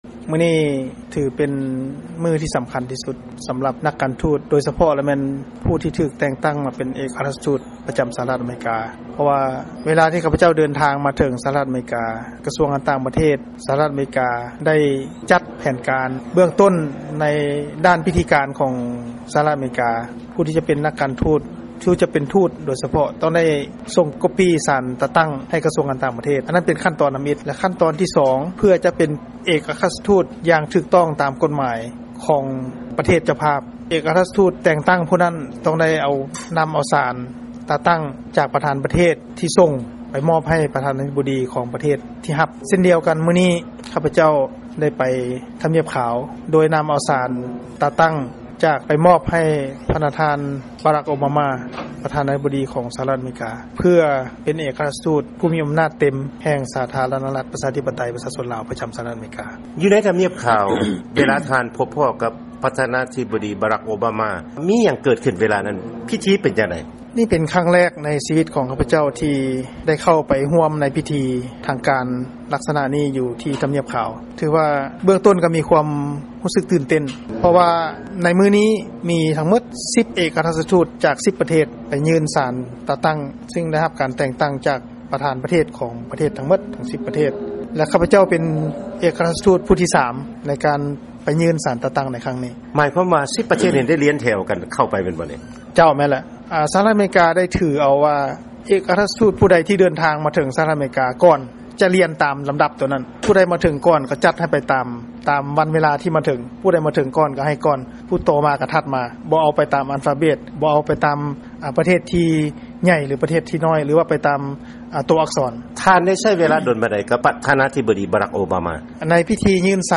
ຟັງການສຳພາດ ພະນະທ່ານ ເອກອັກຄະລັດຖະທູດ ໄມ ໄຊຍະວົງ ຈາກ ສປປ ລາວ